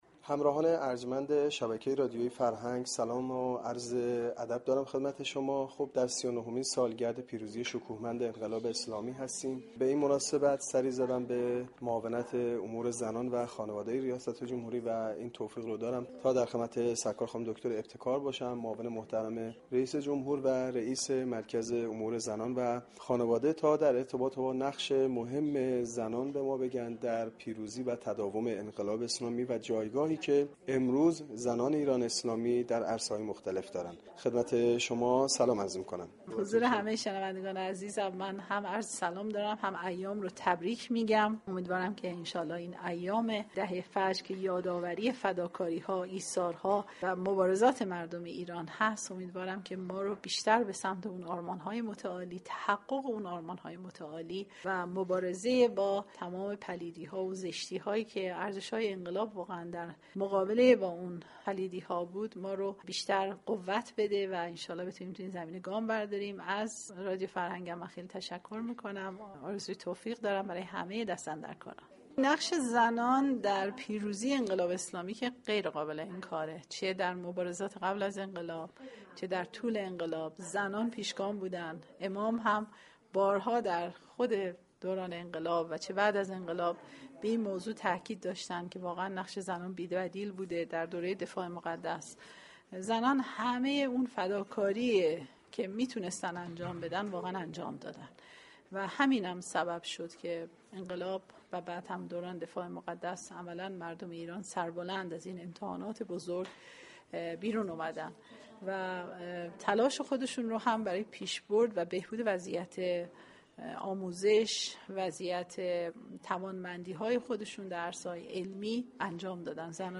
در آستانه ی سی ونهمین سالگرد پیروزی انقلاب اسلامی دكتر معصومه ابتكار معاون رییس جمهور و رییس مركز امور زنان و خانواده در گفتگو با خبرنگار رادیو فرهنگ درباره ی نقش مهم زنان در پیروزی و تداوم انقلاب اسلامی و جایگاه امروز زنان ایرانی در عرصه های مختلف گفت : نقش زنان در پیروزی انقلاب اسلامی غیر قابل انكار است چه در مبارزات قبل از انقلاب و چه در طول انقلاب زنان همیشه پیشگام بودند وحضرت امام (ره) هم به این موضوع تاكید داشتند .